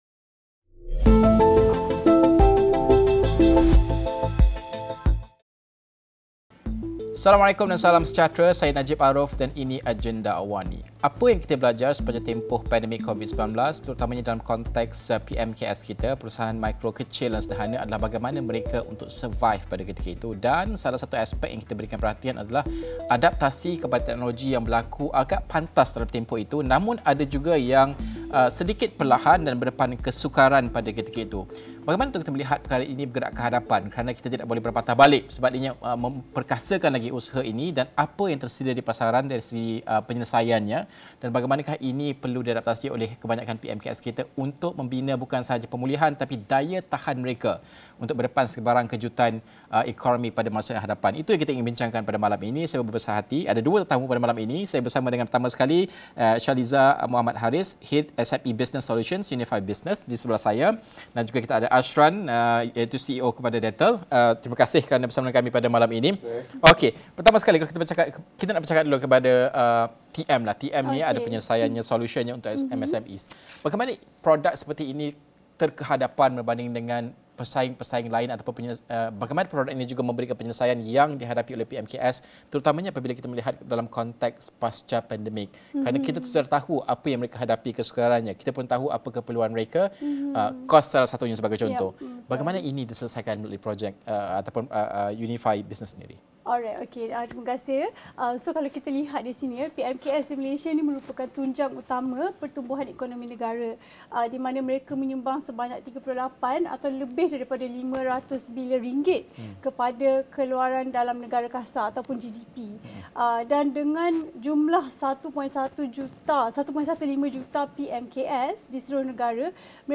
Diskusi 9 malam.